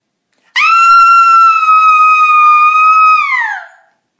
尖叫声
描述：尖叫的女孩害怕
Tag: 尖叫 女孩 呼喊